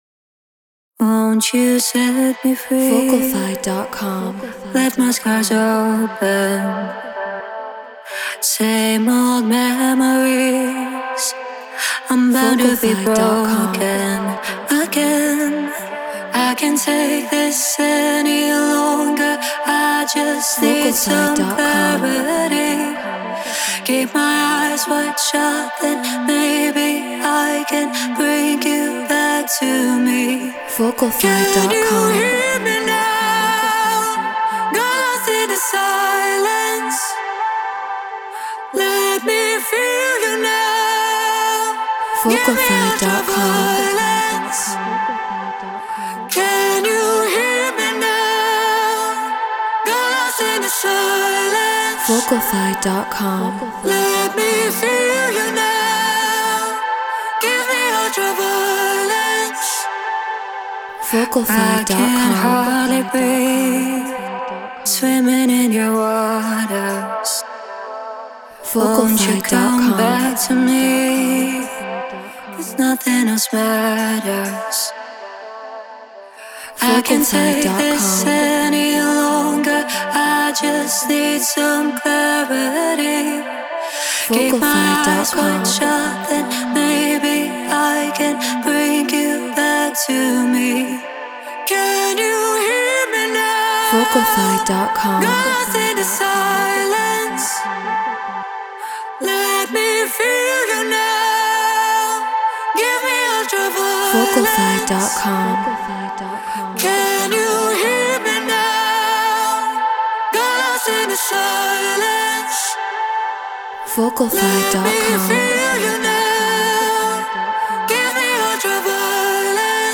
House 127 BPM Amin
Brauner VMX Apogee Elements 88 Ableton Live Treated Room